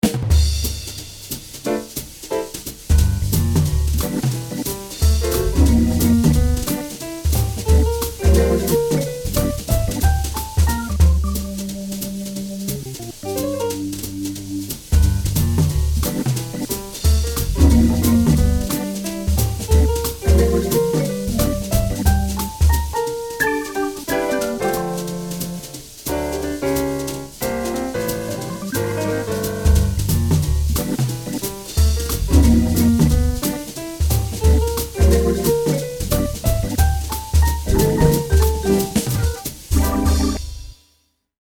Swing 1 (bucle)
swing
piano
batería
melodía